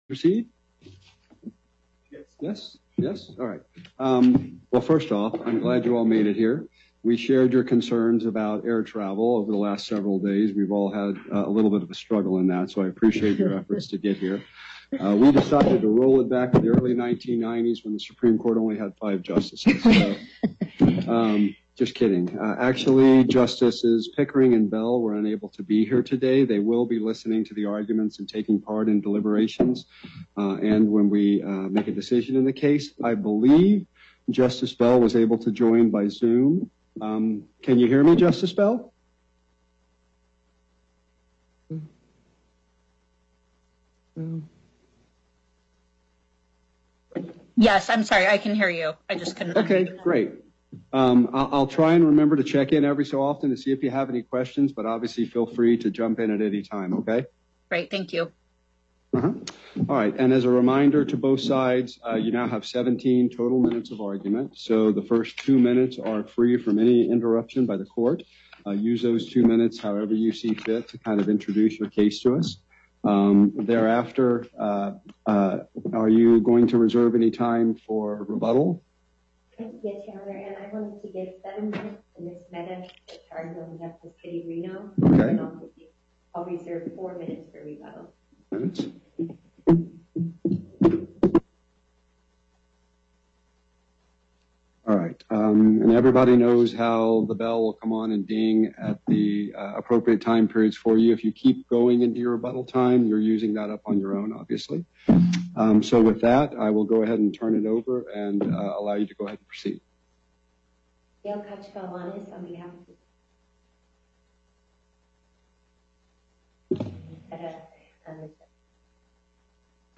Before the En Banc Court, Chief Justice Herndon presiding